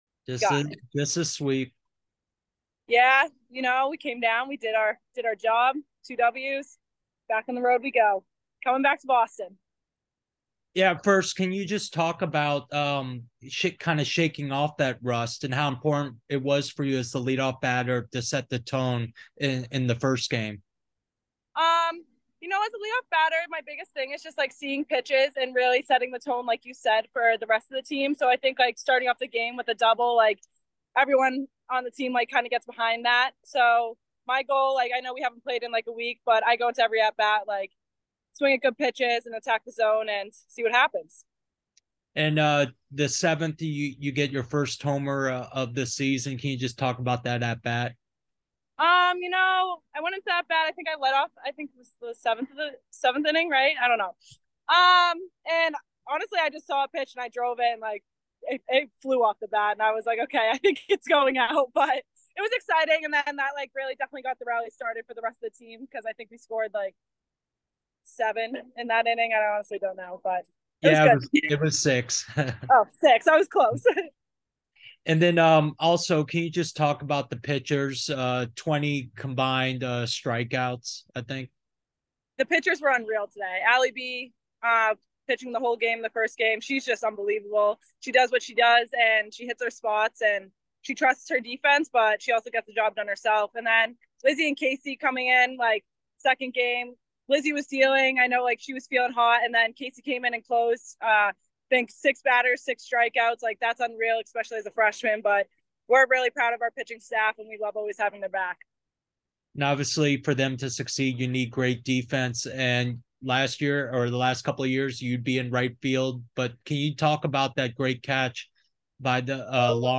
Softball / Fairfield DH Postgame Interview (3-18-23)